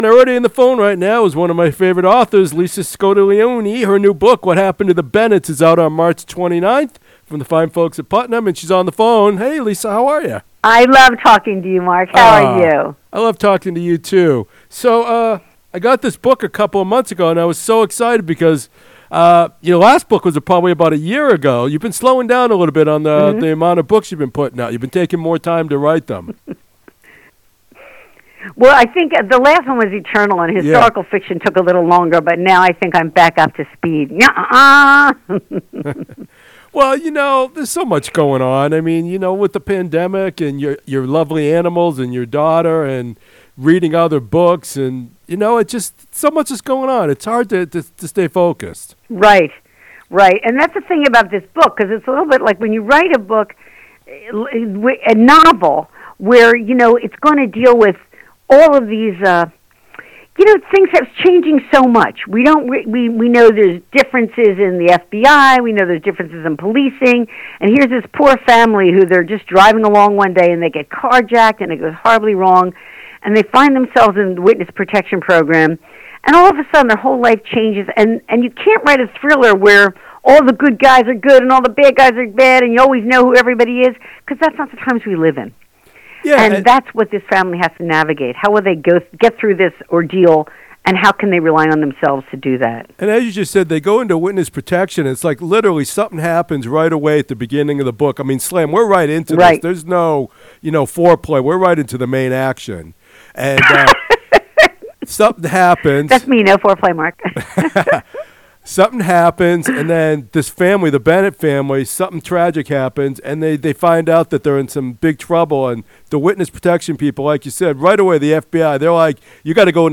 Interview: Author ‘Lisa Scottoline’ Talks Her New Book What Happened To The Bennetts